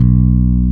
-MM JAZZ B 2.wav